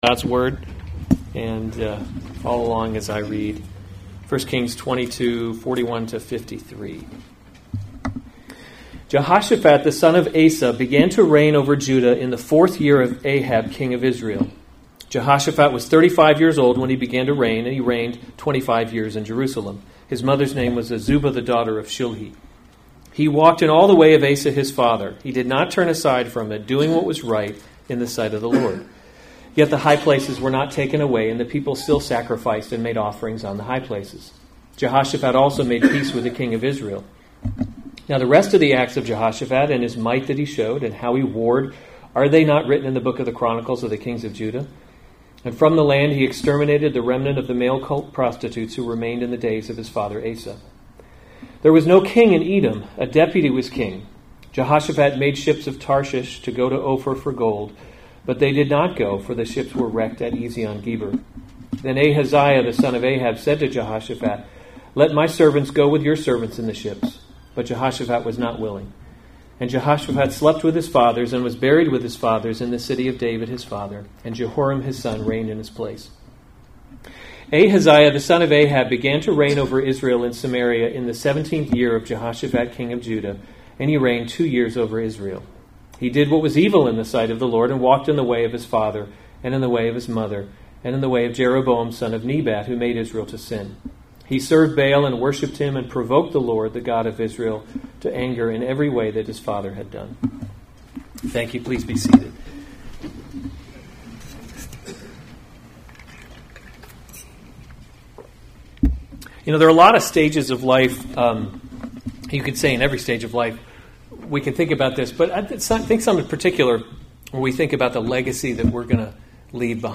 August 3, 2019 1 Kings – Leadership in a Broken World series Weekly Sunday Service Save/Download this sermon 1 Kings 22:41-53 Other sermons from 1 Kings Jehoshaphat Reigns in Judah […]